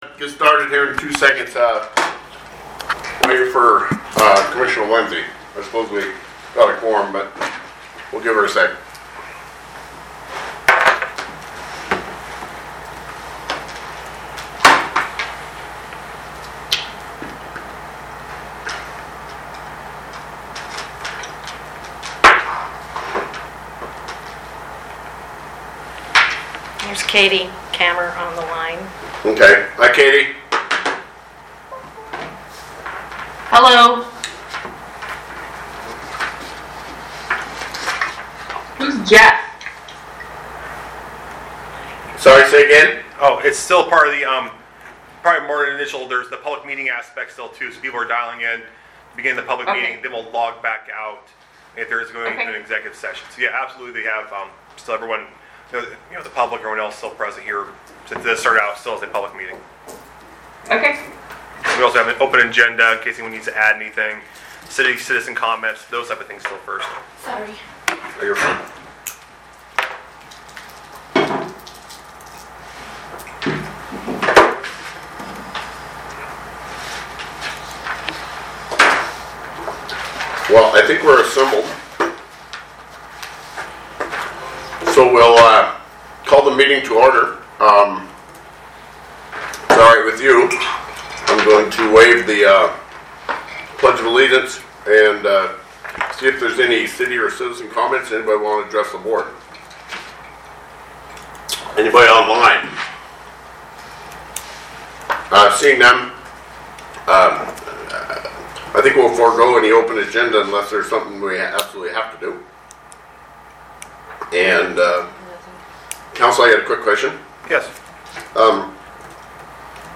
Special Meeting of the Board of Commissioners
Morrow County Bartholomew Building